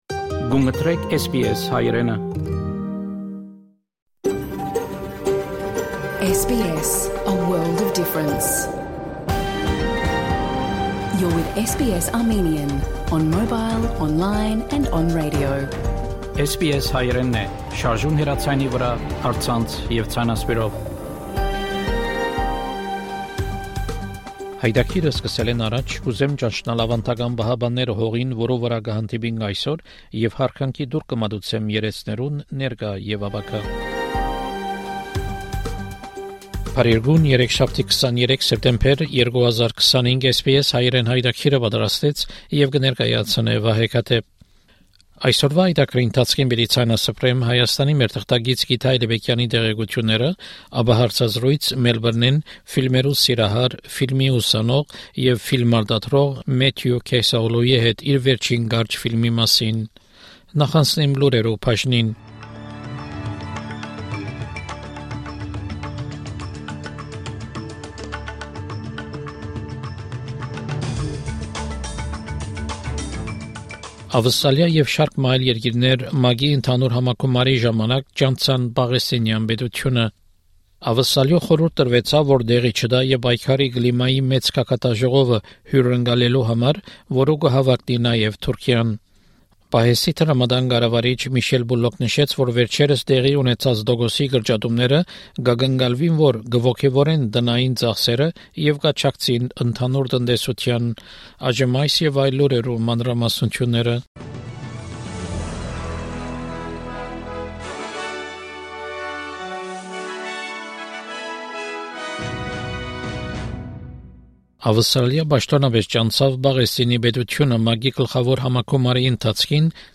SBS Armenian news bulletin from 23 September 2025 program.